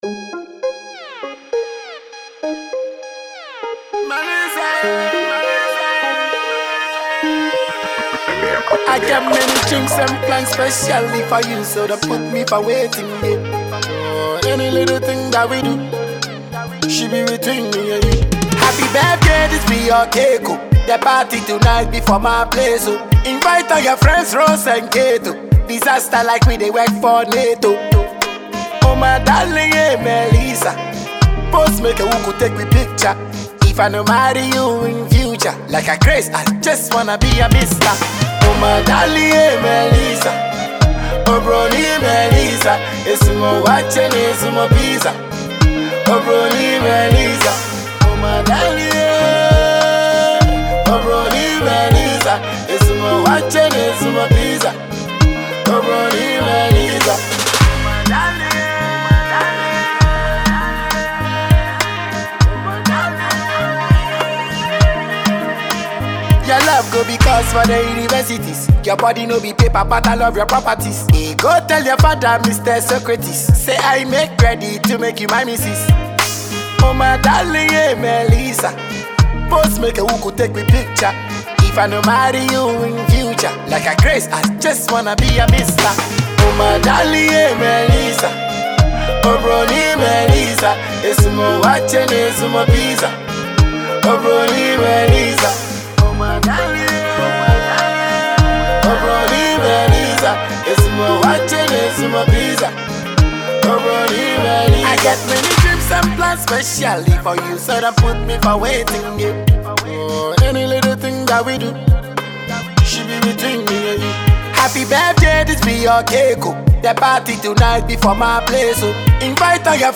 Ghanaian dancehall artist